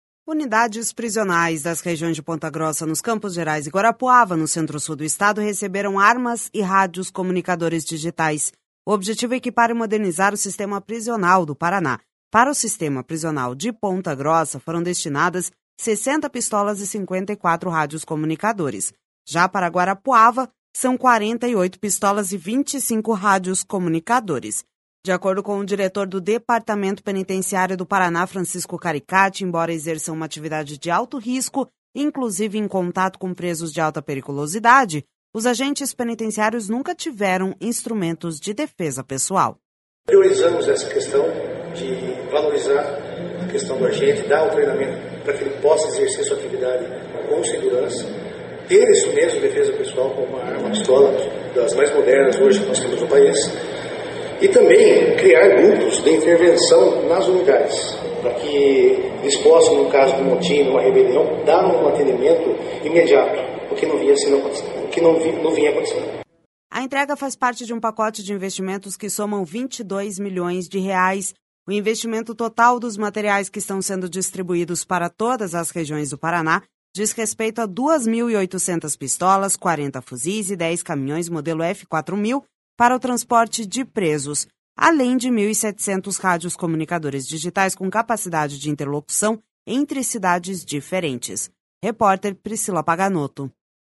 De acordo com o diretor do Departamento Penitenciário do Paraná, Francisco Caricati, embora exerçam uma atividade de alto risco, inclusive em contato com presos de alta periculosidade, os agentes penitenciários nunca tiveram instrumentos de defesa pessoal.// SONORA FRANCISCO CARICATI//A entrega faz parte de um pacote de investimentos que somam 22 milhões de reais.